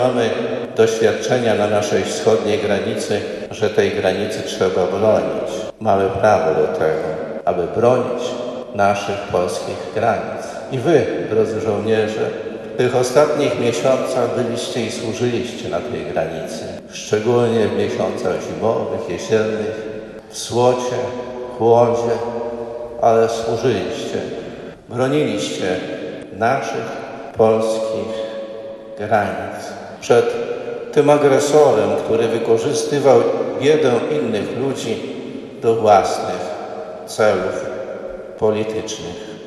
Następnie została odprawiona Msza Święta w intencji Ojczyzny i Żołnierzy Wojska Polskiego w Katedrze Łomżyńskiej.
W homilii, biskup podkreślił jak wielkie zasługi dokonali żołnierze w ostatnim czasie.